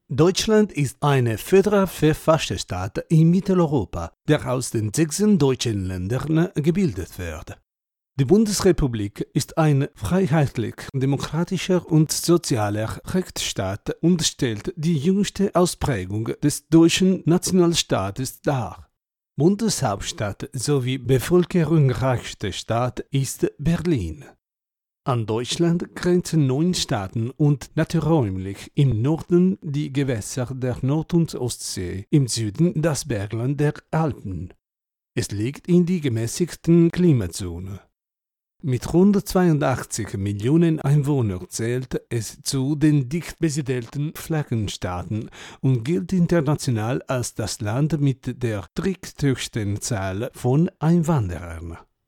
Male
Adult (30-50), Older Sound (50+)
Foreign Language
german_withitalianaccent_demoreel
0923German_WithItalianAccent_DemoReel.mp3